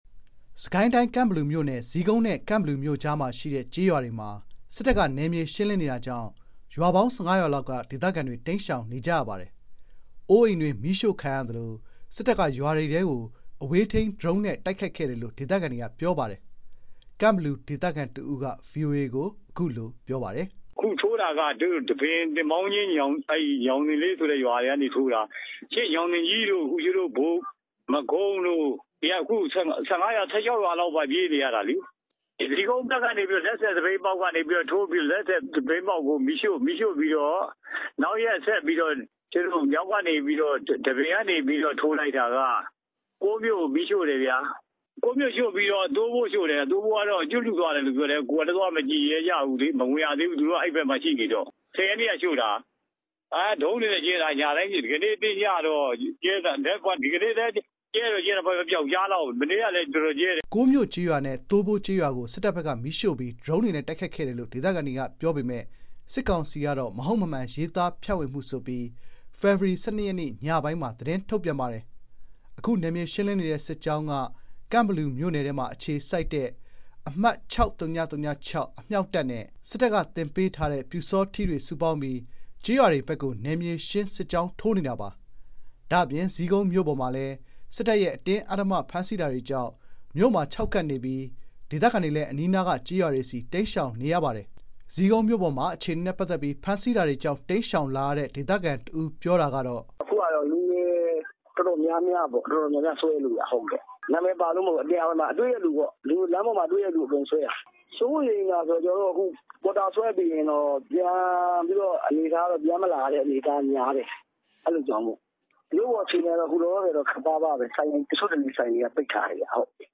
စစ်ကိုင်းတိုင်း၊ ကန့်ဘလူမြို့နယ်ထဲမှာ စစ်တပ်ရဲ့ နယ်မြေရှင်းလင်းရေးအတွင်း ဒေသခံအများအပြား ဘေးလွတ်ရာတိမ်းရှောင်နေရပြီး နေအိမ်တွေလည်း မီးရှို့ခံရပါတယ်။ ဇီးကုန်မြို့မှာလည်း အဖမ်းအဆီးတွေကြောင့် လူငယ်တွေရှောင်တိမ်းနေရပါတယ်။ ရန်ကုန်က သတင်းပေးပို့ထားပါတယ်။
စစ်ကိုင်းတိုင်း ကန့်ဘလူမြို့နယ် ဇီးကုန်းနဲ့ ကန့်ဘလူမြို့ကြားမှာရှိတဲ့ ကျေးရွာတွေမှာ စစ်တပ်က နယ်မြေ ရှင်းလင်းနေတာကြောင့် ရွာပေါင်း ၁၅ ရွာလောက်က ဒေသခံတွေ တိမ်းရှောင်နေကြရပါတယ်။ အိုးအိမ်တွေ မီးရှို့ခံရသလို စစ်တပ်က ရွာတွေထဲကို အဝေးထိန်း ဒရုန်းနဲ့ တိုက်ခိုက်တယ်လို့ ဒေသခံတို့က ပြောပါတယ်။ ကန့်ဘလူ ဒေသခံ တဦးက ဗွီအိုအေကို အခုလို ပြောပါတယ်။